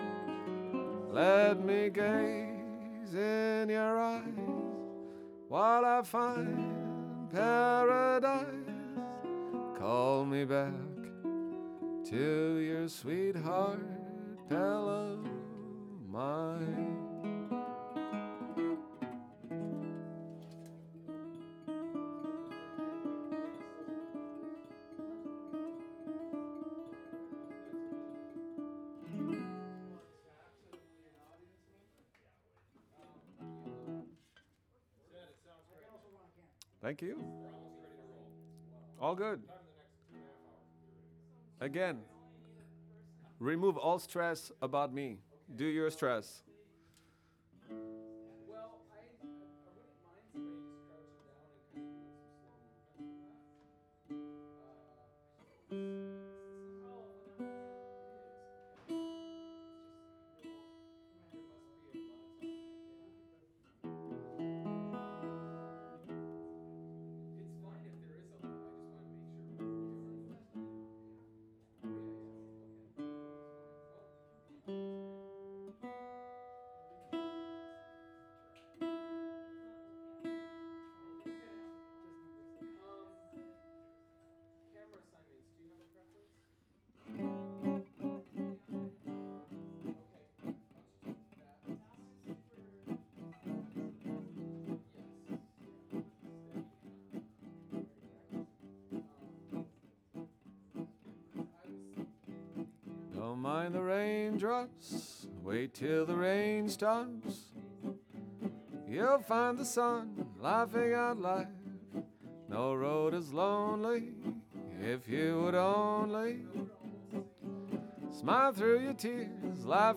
VOX_01.wav